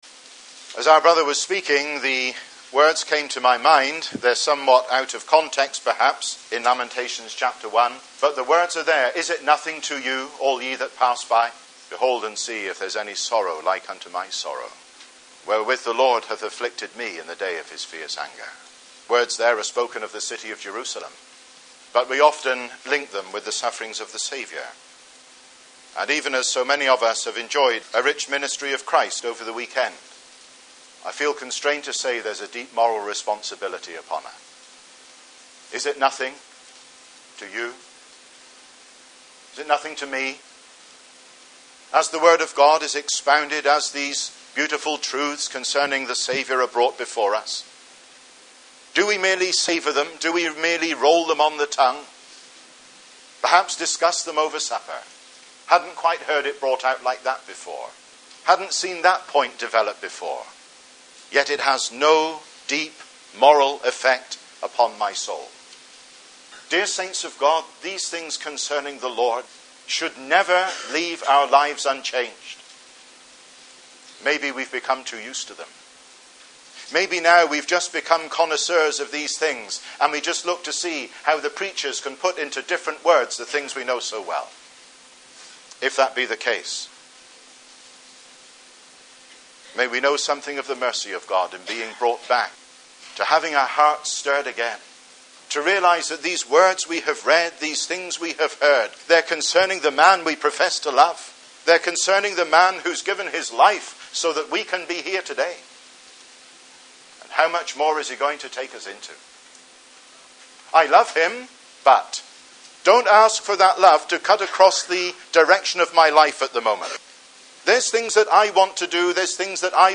2006 Easter Conference